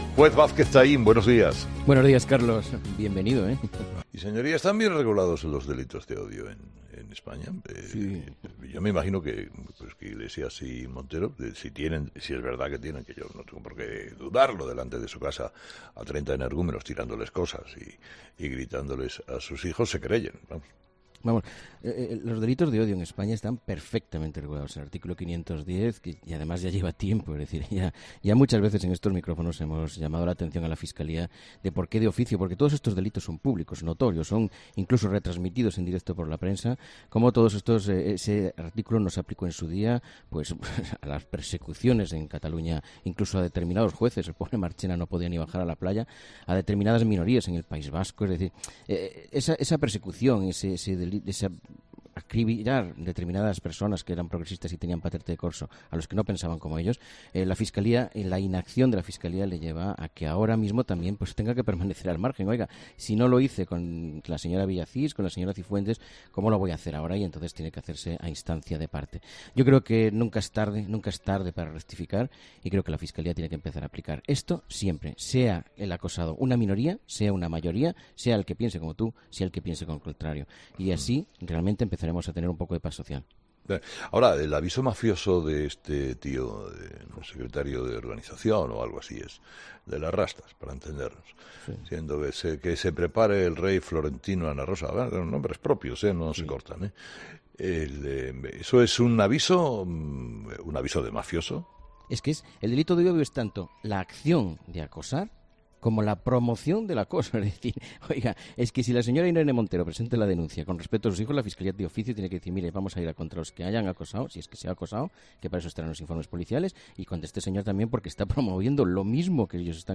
Entrevistado: "José Antonio Vázquez Taín"